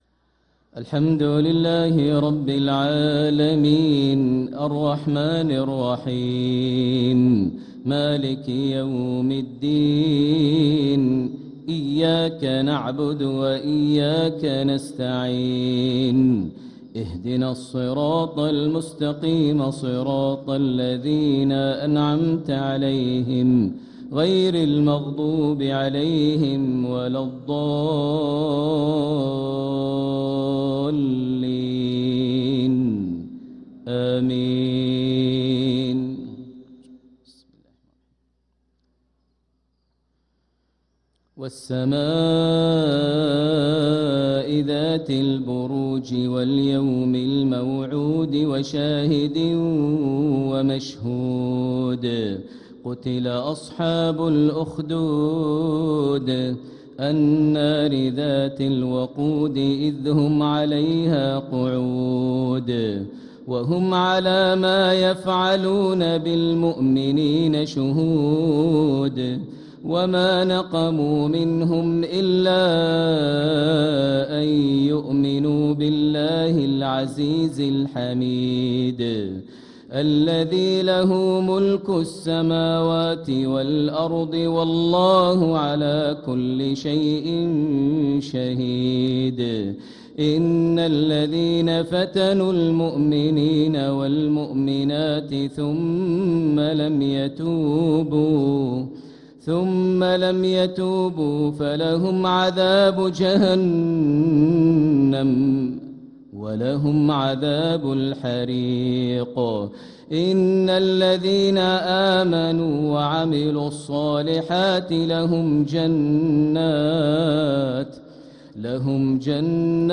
صلاة المغرب للقارئ ماهر المعيقلي 27 صفر 1446 هـ
تِلَاوَات الْحَرَمَيْن .